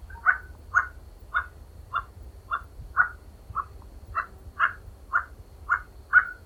sounds_toucan_01.ogg